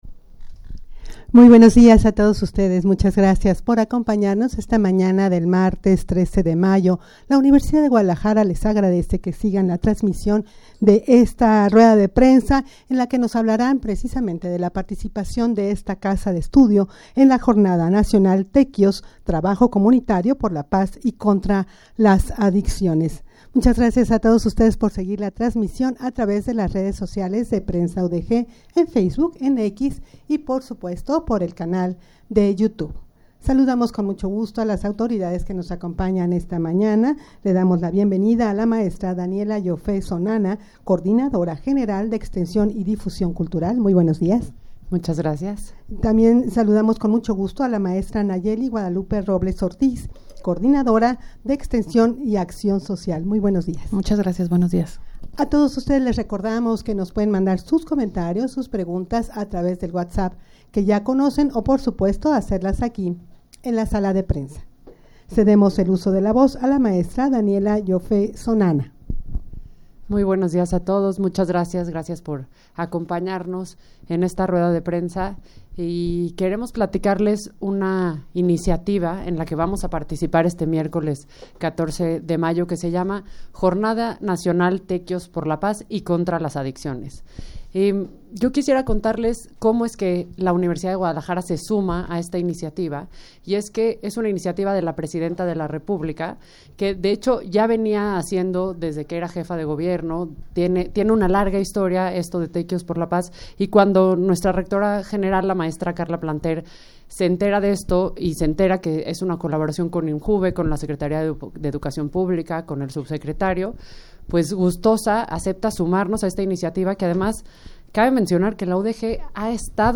Audio de la Rueda de Prensa
rueda-de-prensa-para-dar-a-conocer-la-participacion-de-la-udeg-en-la-jornada-nacional-tequios.mp3